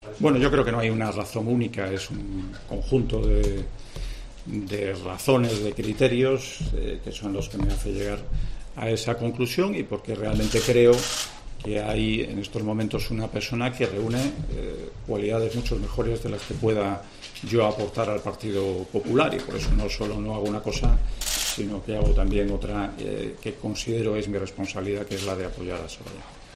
"Creo que no estoy preparado para ese reto", ha dicho sobre el liderazgo del partido en una comparecencia ante los medios de comunicación en Santander.